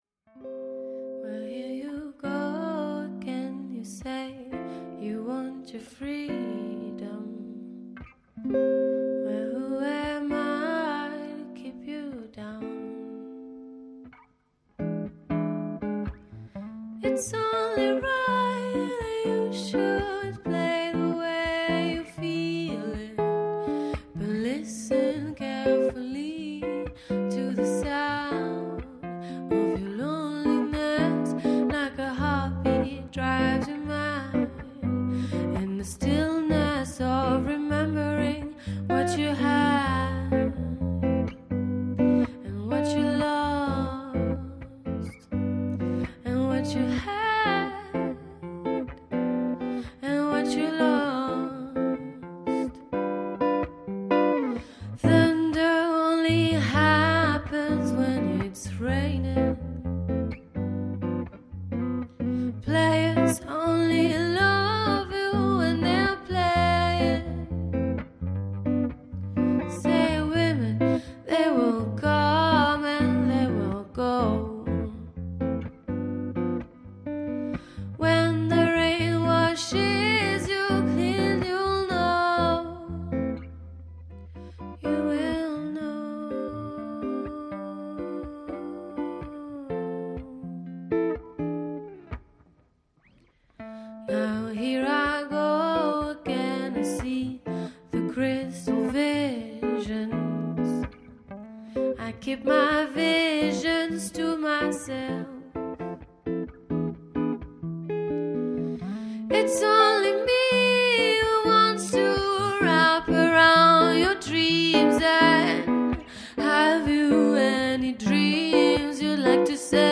Jazz duo performing modern songs